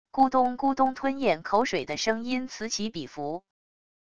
咕咚咕咚吞咽口水的声音此起彼伏wav音频